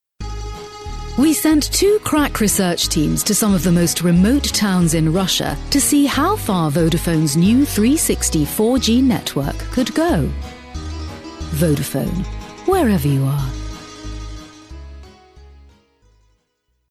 English british female voice over artist. sophisticated, warm, friendly , business voice
Sprechprobe: Werbung (Muttersprache):
I have a clear friendly warm british voice.